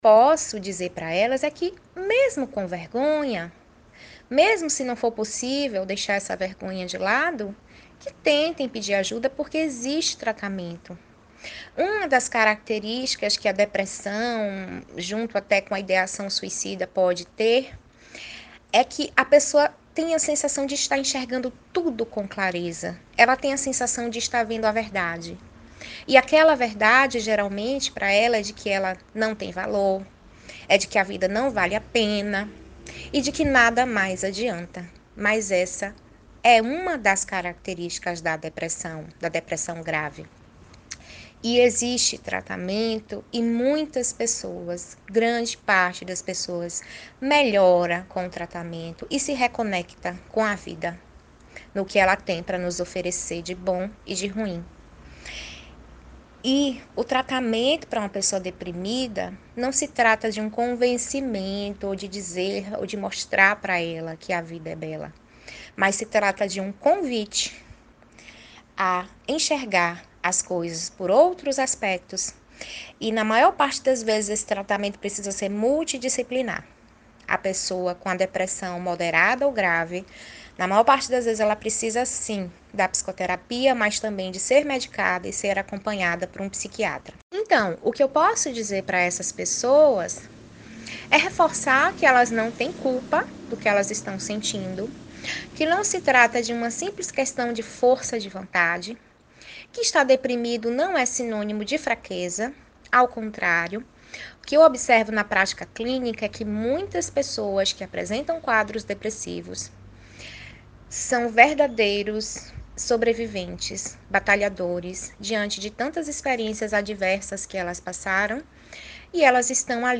Psicóloga